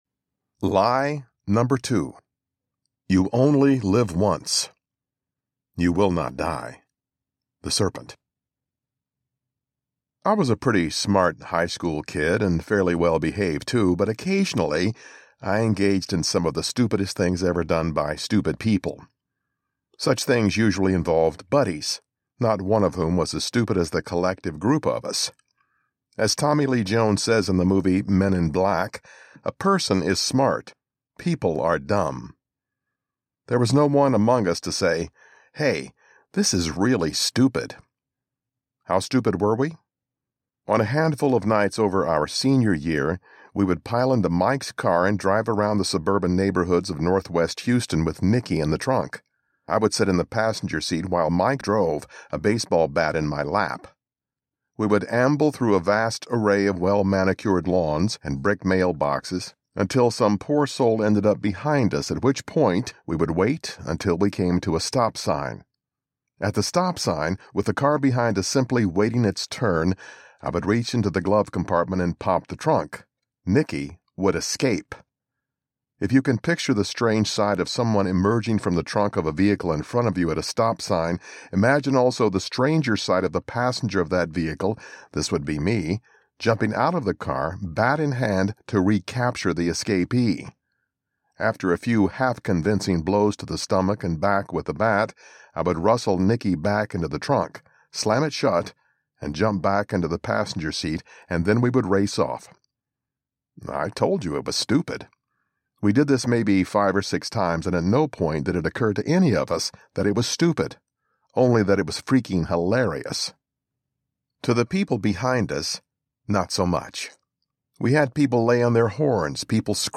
The Gospel According to Satan Audiobook
Narrator
5.3 Hrs. – Unabridged